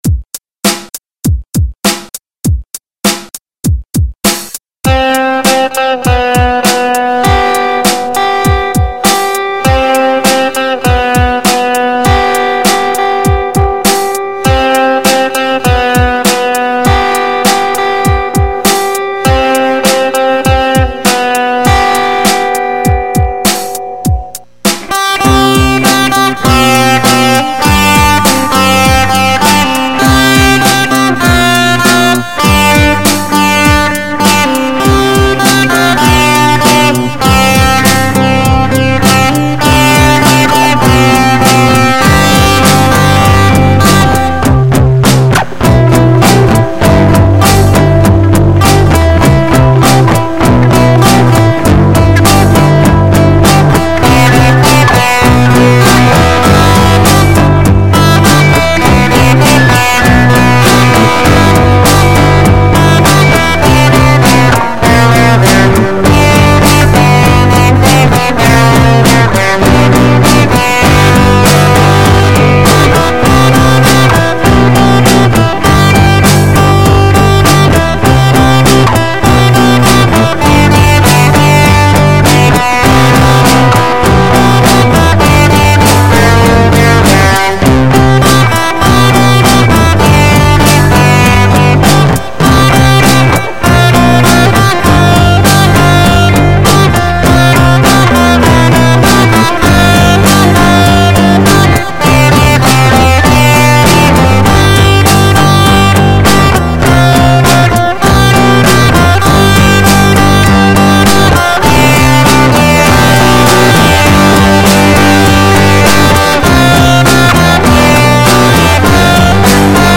1. An Ibanez ArtCore AF75 hollow-body electric guitar, plugged into
4. Using Hammerhead Rhythm Station (run via Wine) for drums
I know the mixing is terrible and there’s distortion. I know I hit some sour notes and my grasp of Key is, um, fragile.